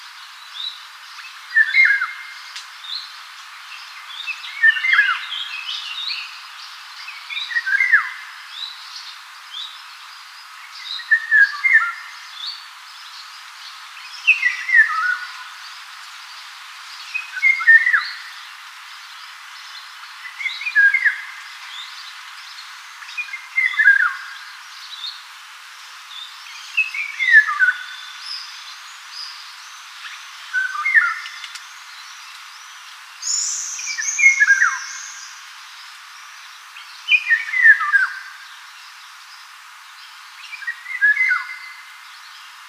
Canto
O canto do Papafigos é un dos máis melodiosos das aves europeas, composto por unha serie de notas claras e flautadas que resoan nos bosques durante a primavera e o verán.